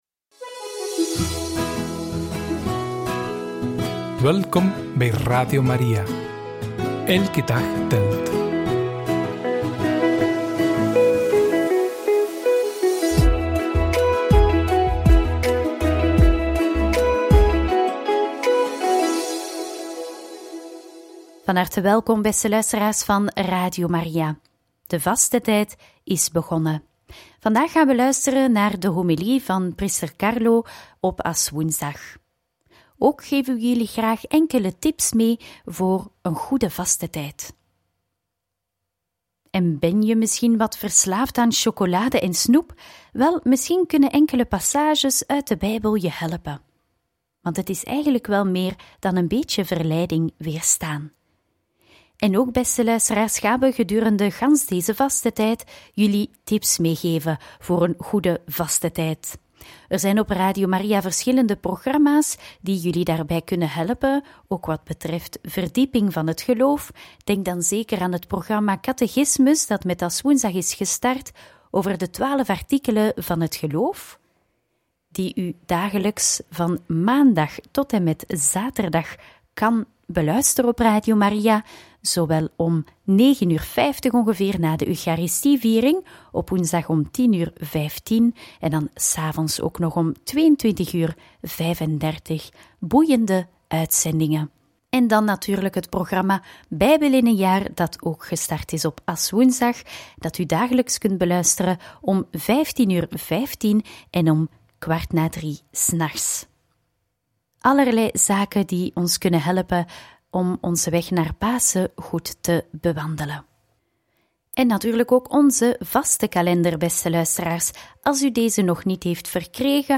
Kan je de verleiding van zoetigheid niet weerstaan? – Homilie op Aswoensdag – ‘Inleiding tot het devote leven’ – Radio Maria